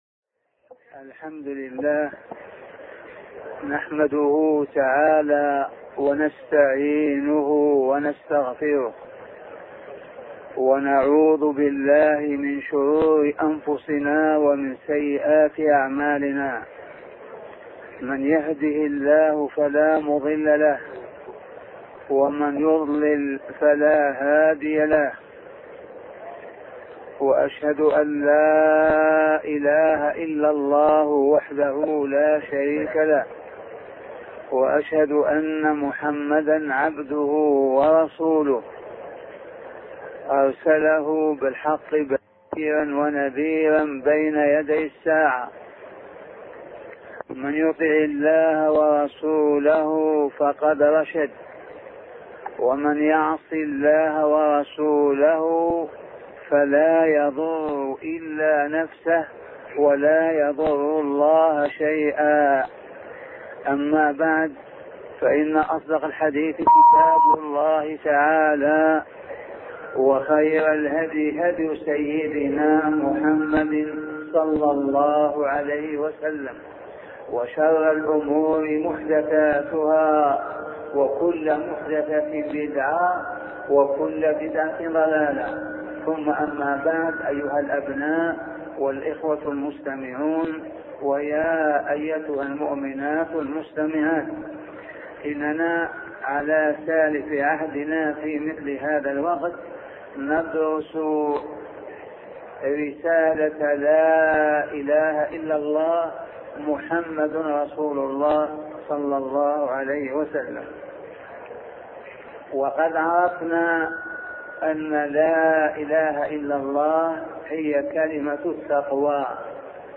سلسلة محاطرات بعنوان معنى لا إله إلا الله